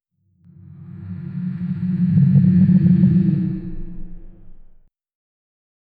Create a rich, dynamic soundscape for a surreal scene where an enormous pizza sauce bottle pours sauce onto a massive building. The sound should start with a deep, resonant "whoosh" 0:06 Created Mar 12, 2025 11:04 PM
create-a-rich-dynamic-sou-qbb5yli6.wav